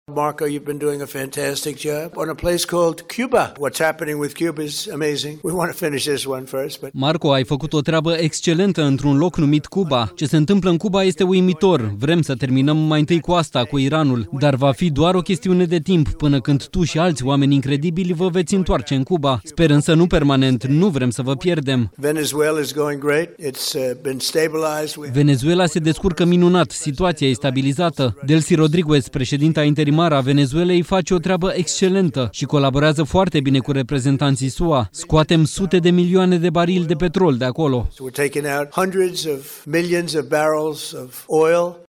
Declarațiile au fost făcute la ceremonia de primire a campioanei ligii de fotbal din Statele Unite, Inter Miami.
06mar-12-Trump-Cuba-urmeaza-tradus.mp3